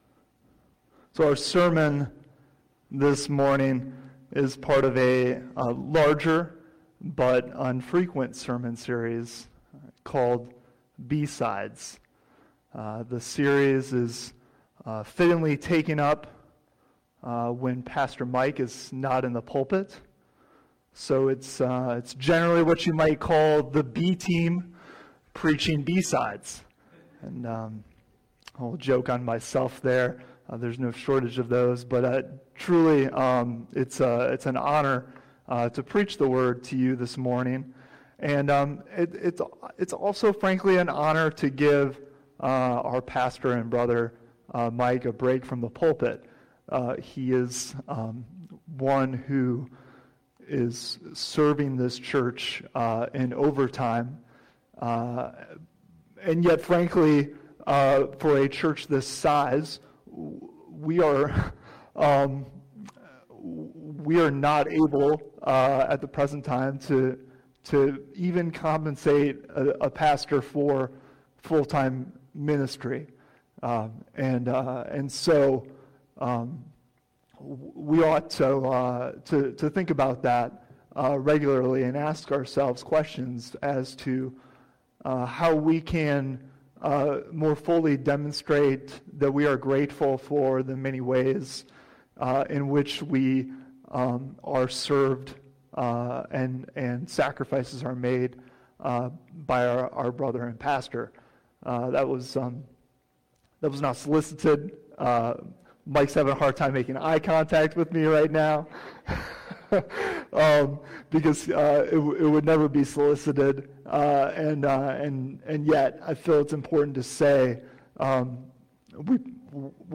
Service Morning Worship
June 21 Sermon Only Bible References 1 Chronicles 4:9 - 10